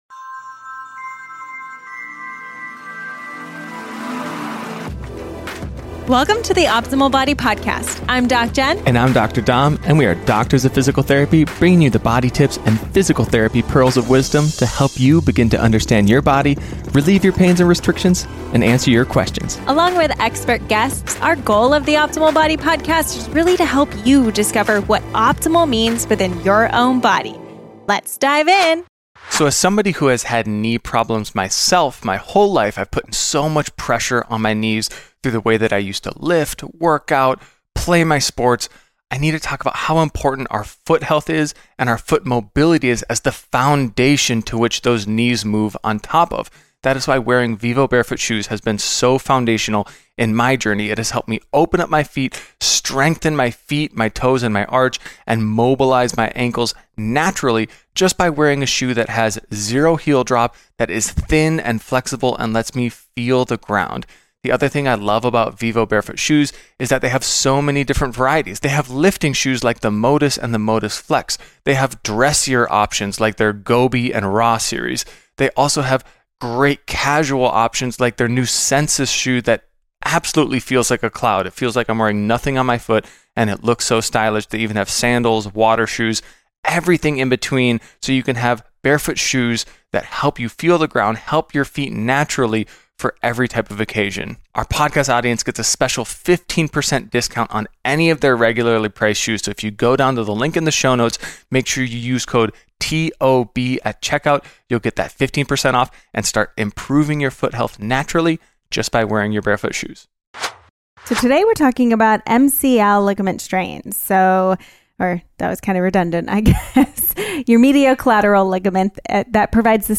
both doctors of physical therapy, discuss MCL (medial collateral ligament) strains. They explain the anatomy and function of the MCL, common injury mechanisms, and the grading of MCL injuries. Emphasizing the importance of foot health and mobility for knee stability, they outline the rehabilitation process, highlighting the role of physical therapy in recovery.